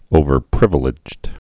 (ōvər-prĭvə-lĭjd)